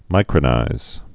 (mīkrə-nīz)